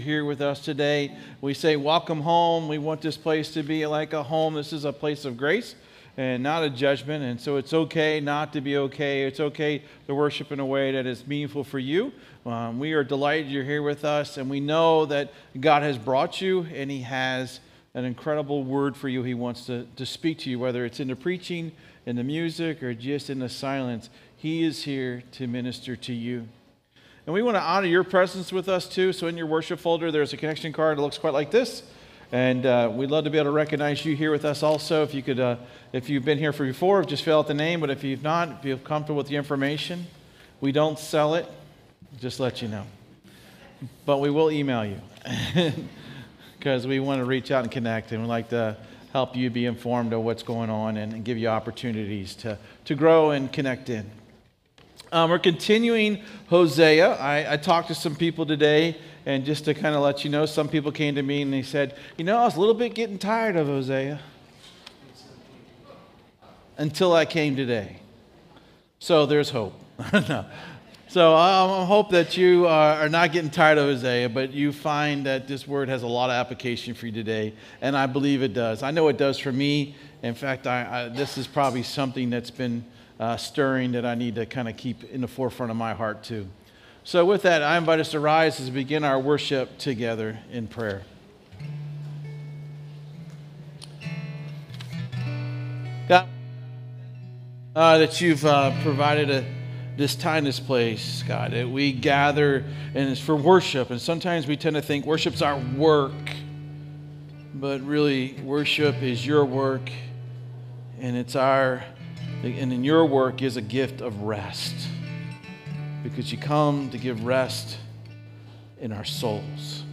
98 Campus | Hope on the Beach Church
This sermon explores the warning of Hosea that God’s people often chase “the wind” by placing their trust in false saviors, worldly alliances, and empty pursuits instead of resting in God’s covenant love. When we abandon the Lord, our efforts produce only a whirlwind—activity without substance and fruit that does not last.